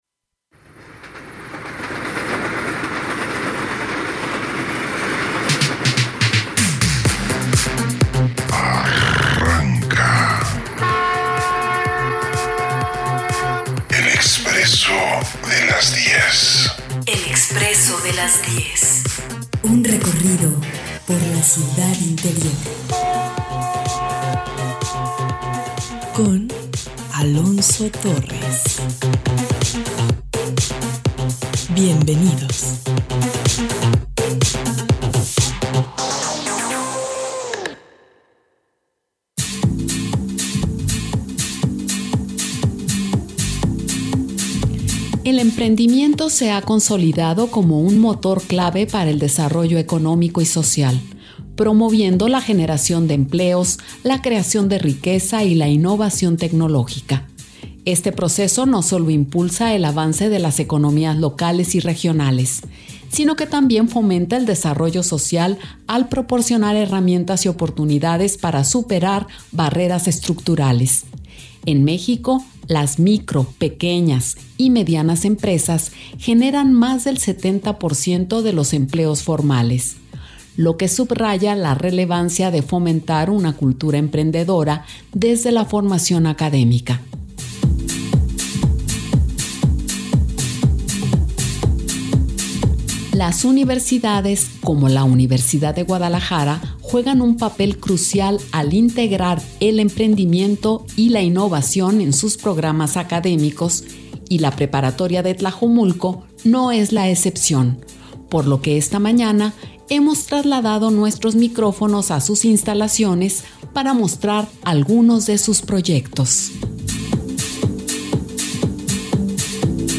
Las universidades, como la UDG juegan un papel crucial al integrar el emprendimiento y la innovación en sus programas académicos, la preparatoria de Tlajomulco no es la excepción, por lo que en este podcast de El Expresso de las 10 conoceremos algunos de sus proyectos en vivo desde sus instalaciones